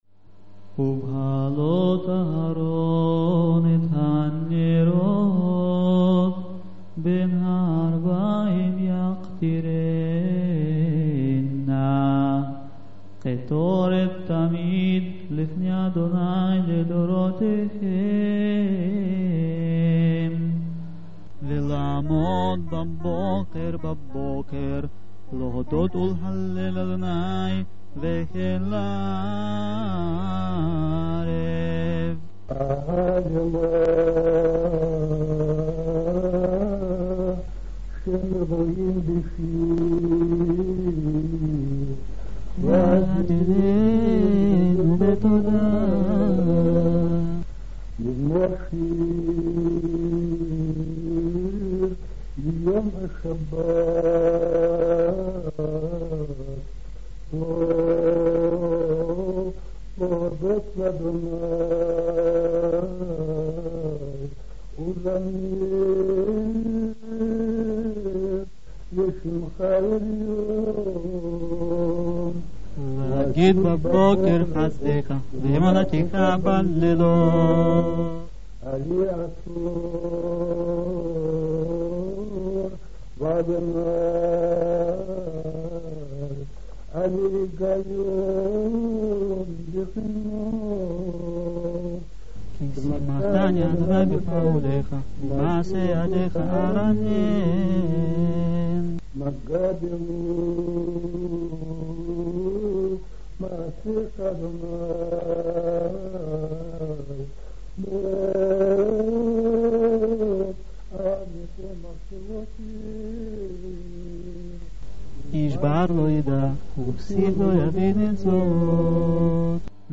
Before you it is a Saturday Evening Prayer on a tradition of Crimean Karaites.
The prayer must be read in responsive by a hazzan and a community, in Hebrew, in musical tradition and a pronunciation, accepted to Crimean Karaites, which is some differ in other communities (Egypt, Israel, Turkey and Lithuania).